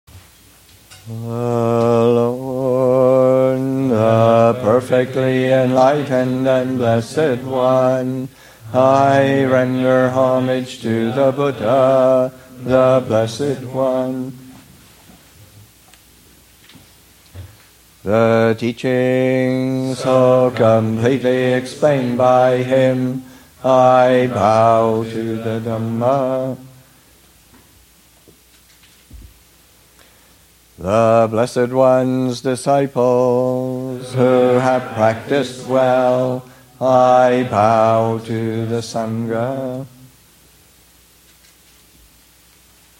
Abhayagiri's 20th Anniversary, Session 16: Closing Remarks
Chanting: Closing Homage (Amaravati Chanting Book, p. 16).